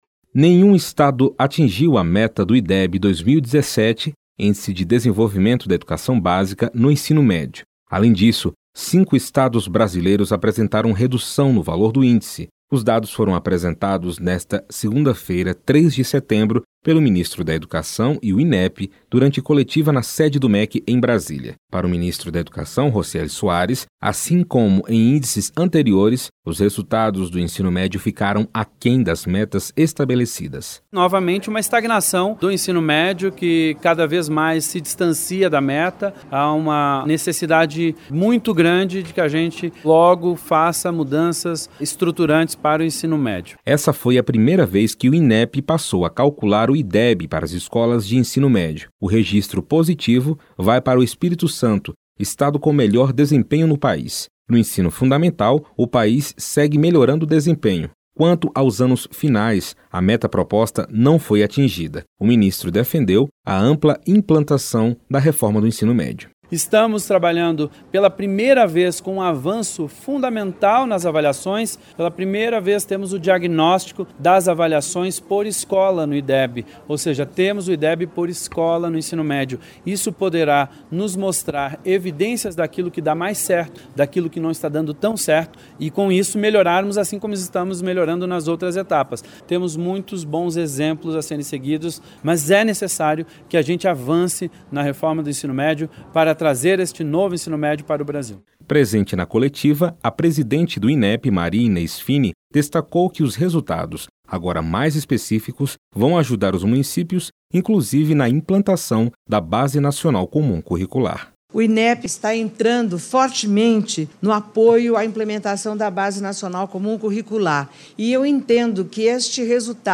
matéria-ideb.mp3